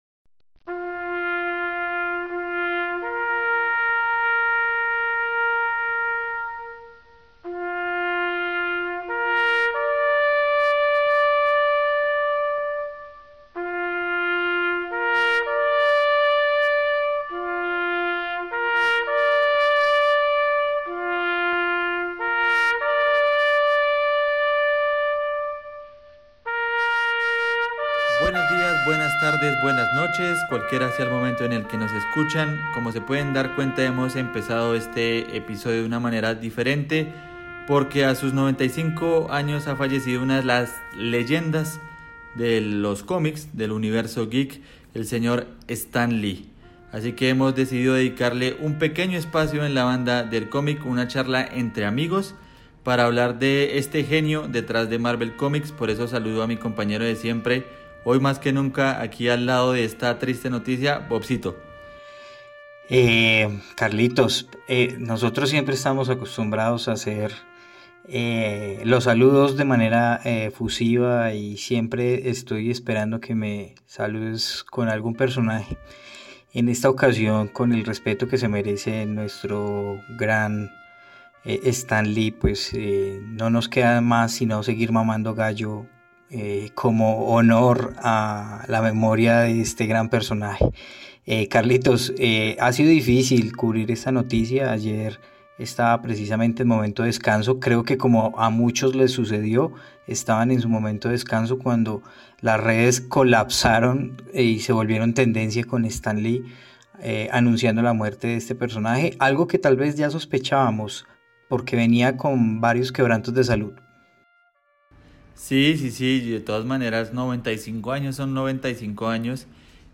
Dos amigos y fanáticos geek reaccionan a la muerte del genio de Marvel Cómics que cambió para siempre el mundo de las historietas.